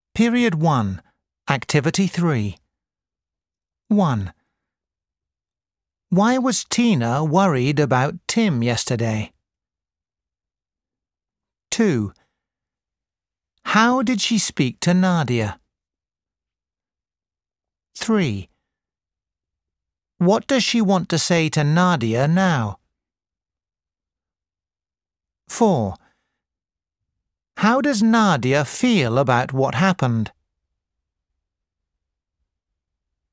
دروس الاستماع